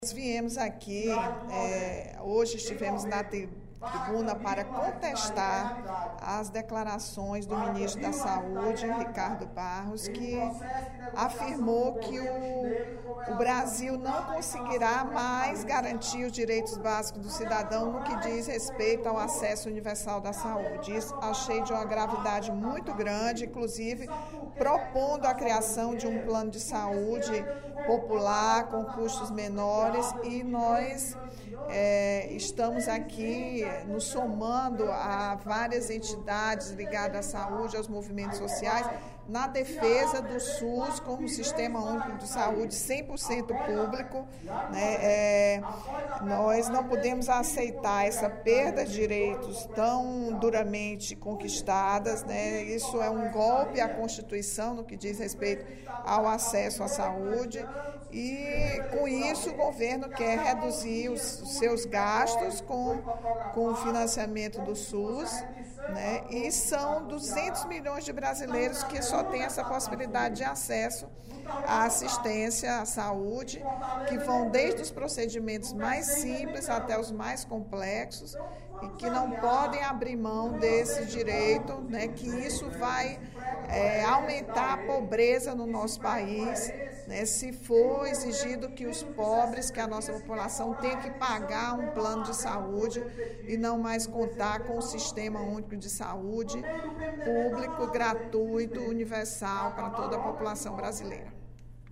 A deputada Rachel Marques (PT) contestou, no primeiro expediente da sessão plenária desta sexta-feira (08/07), as últimas declarações do Ministro da Saúde, Ricardo Barros, ao afirmar que o Estado não terá capacidade financeira suficiente para permitir alguns direitos constitucionais, como a saúde.